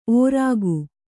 ♪ ōrāgu